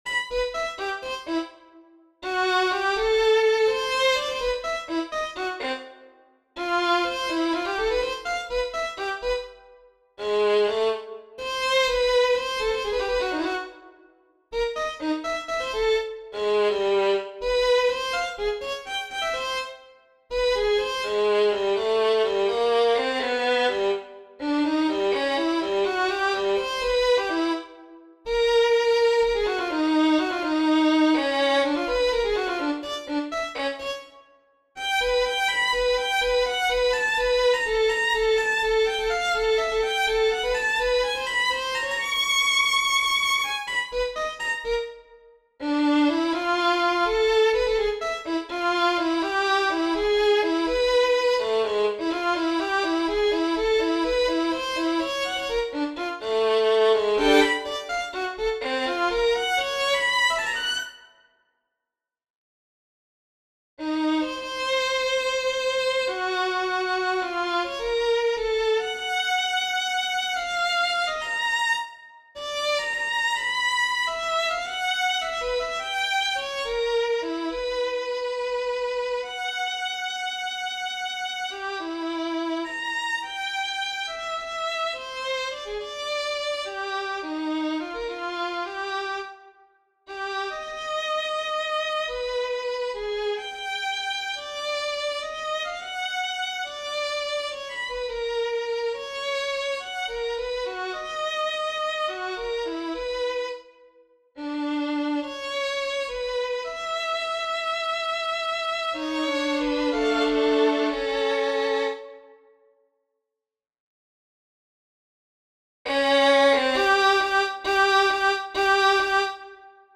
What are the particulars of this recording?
The MIDI articulation could be better, but I haven’t focused on it since they’re meant to be performed by a real player.